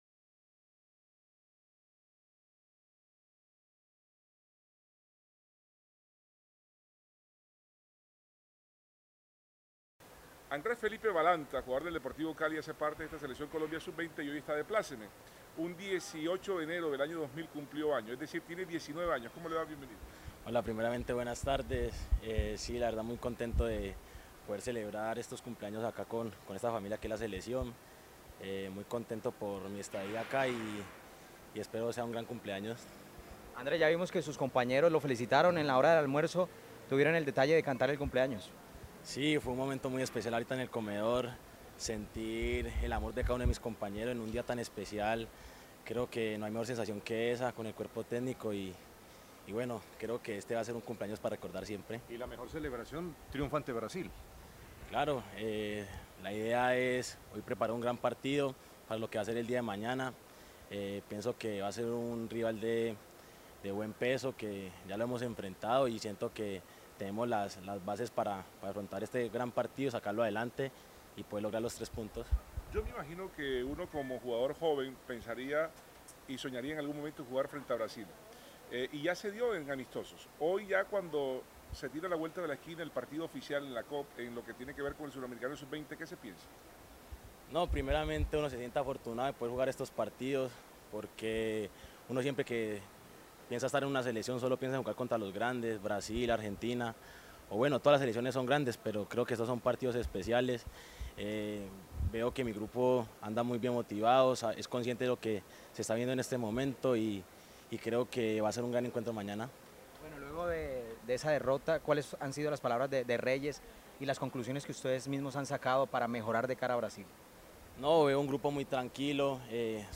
atendieron a la prensa:
(Mediocampista)